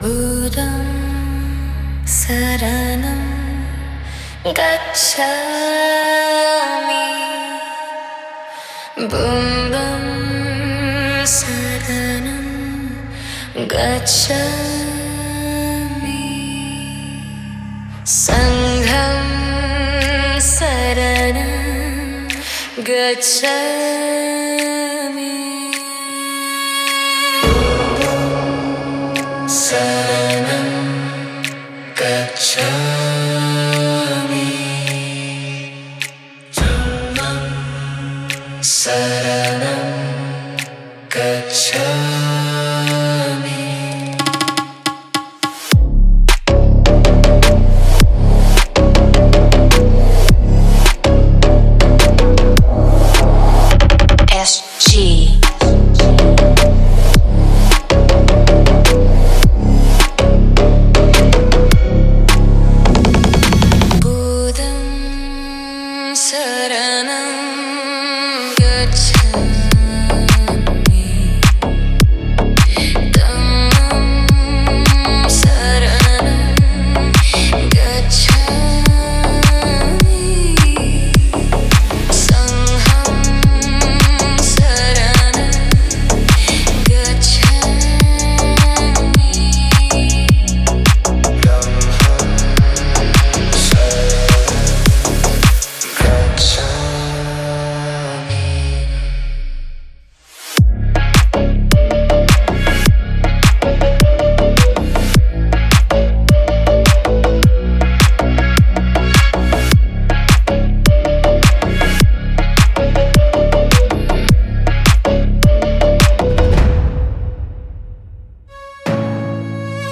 Category: MARATHI DJ